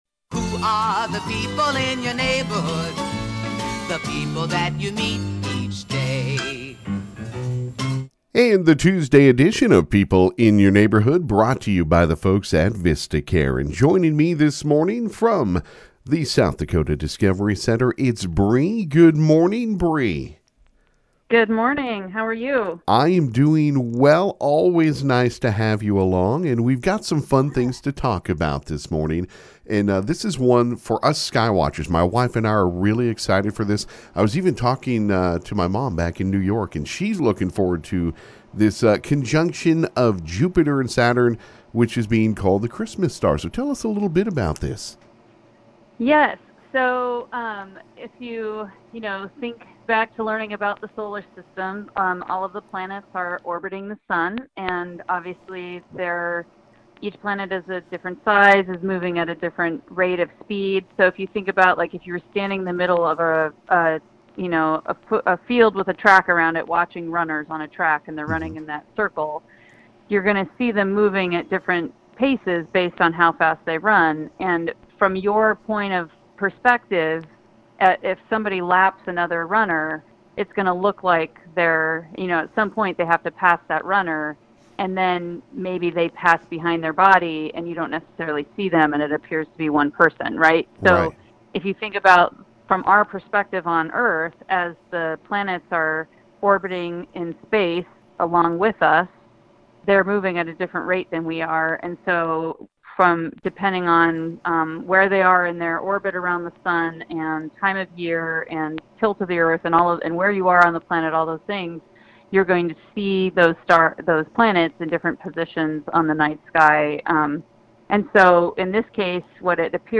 called in and talked about the “Christmas Star”